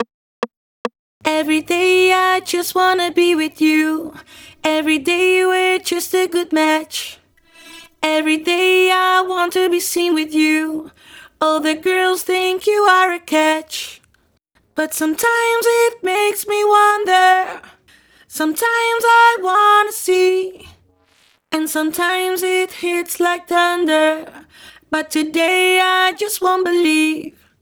Vocals RETUNE FX.wav